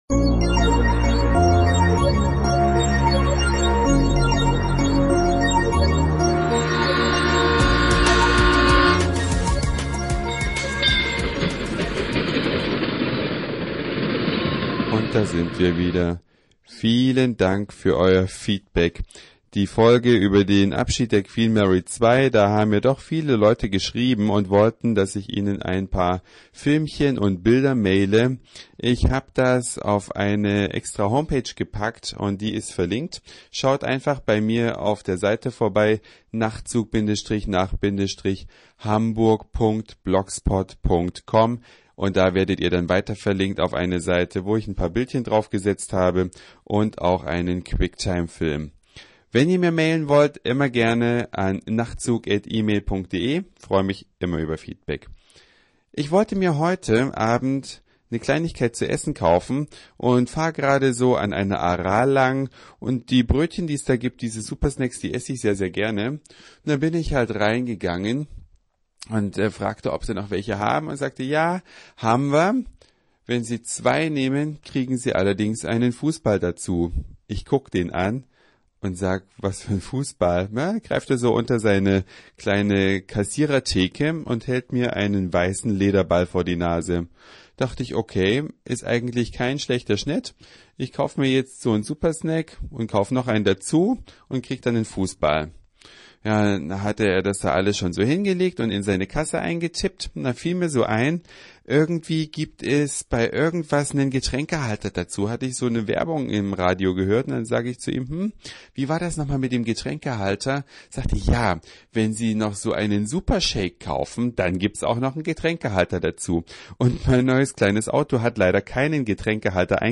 aus der ganzen Welt nach Hamburg gekommen sind. Und Ihr seid live
dabei, ich melde mich mit einer Soundseeingtour aus dem Hamburger